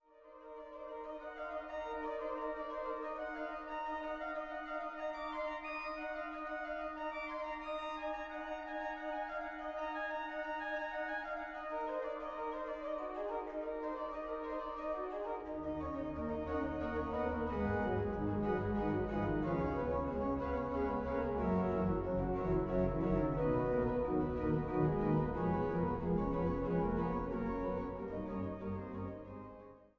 Einweihungskonzertes vom 03.11.2000